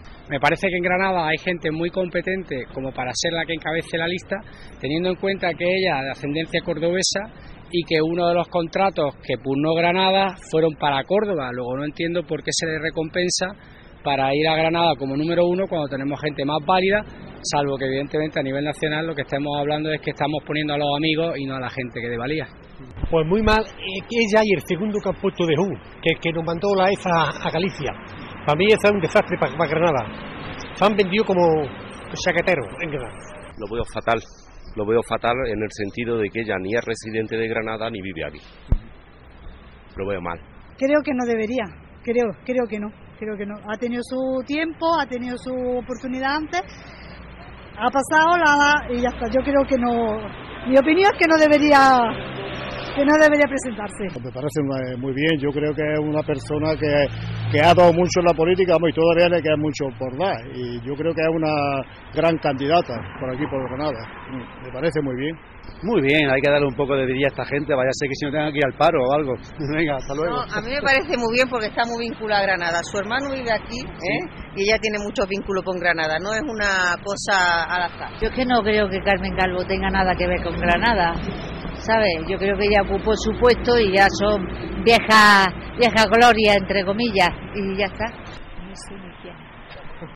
Hemos preguntado a los granadinos sobre la candidatura socialista al Congreso de los diputados encabezada por Carmen Calvo y José Antonio Rodríguez Salas, ex alcalde de Jun